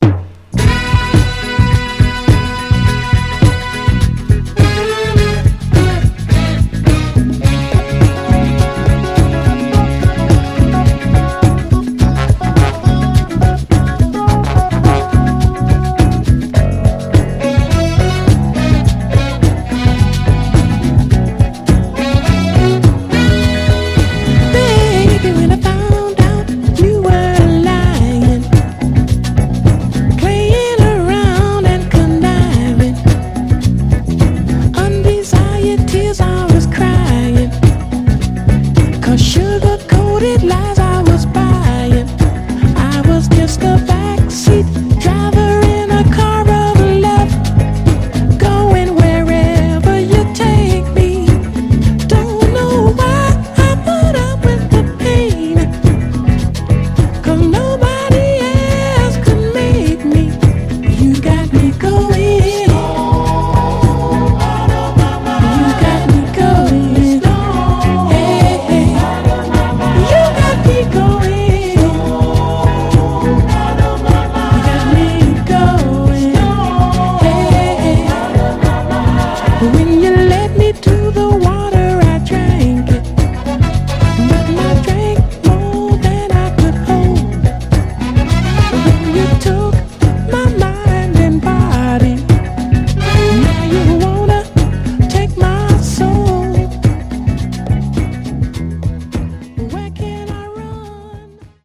タフなヴァイナル・プレス。
※試聴音源は実際にお送りする商品から録音したものです※